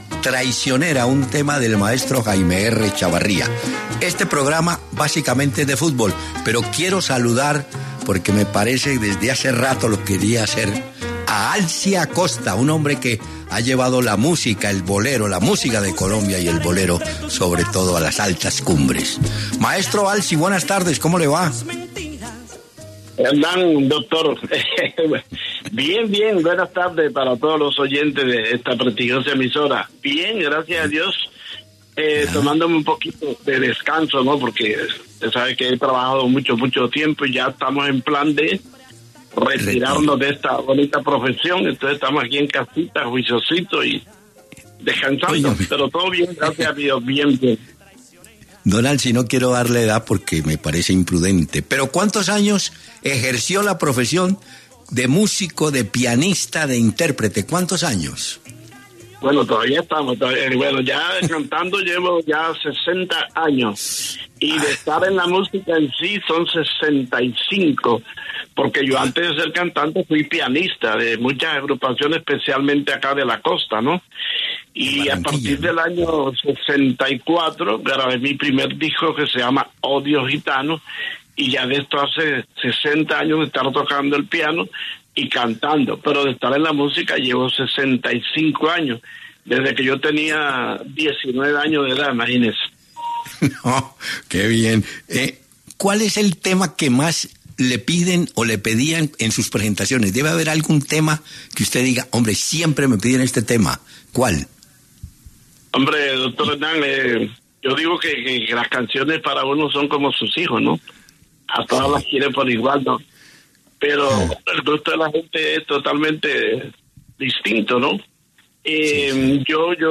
Alcibiades Alfonso Acosta, conocido artísticamente como Alci Acosta, conversó con Peláez De Francisco en La W, un corto homenaje que Hernán Peláez le rindió a este histórico artista colombiano.